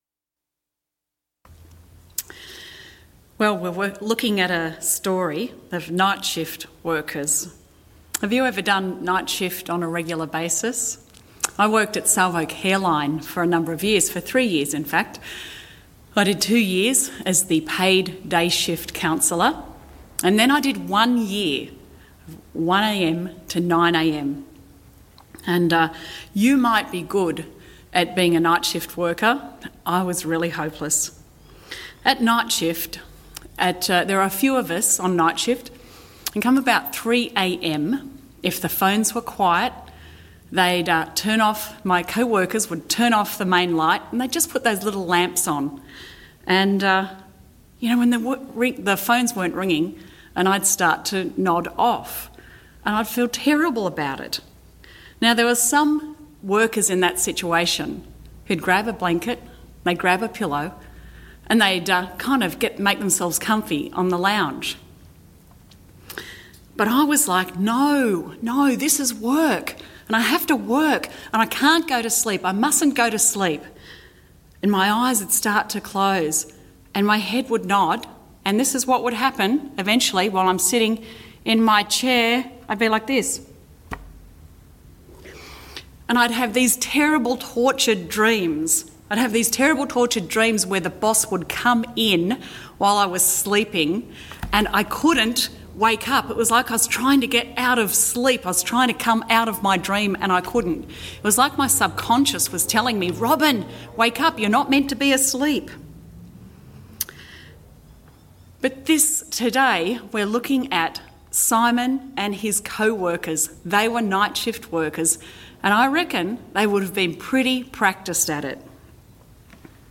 Guest Preacher
Sermon